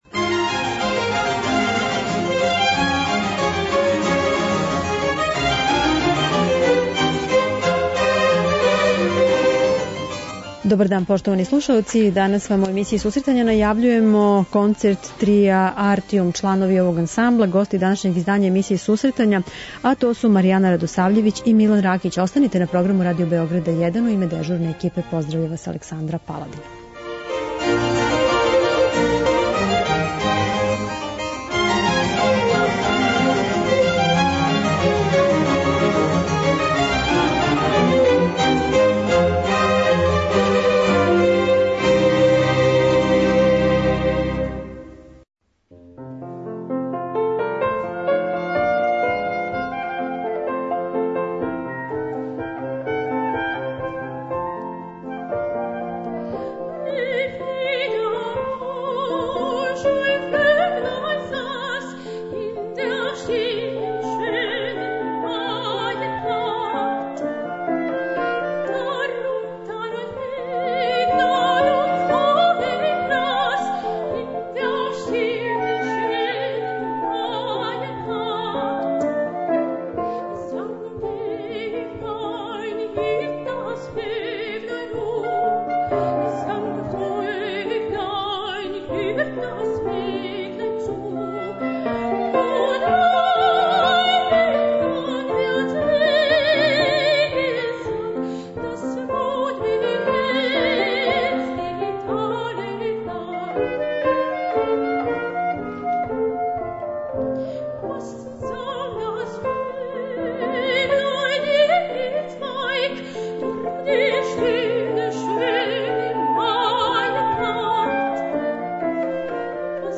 Емисија за оне који воле уметничку музику.